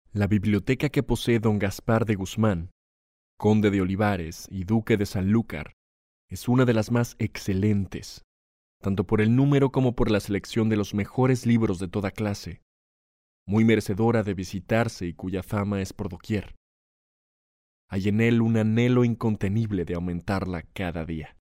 Mexican voice over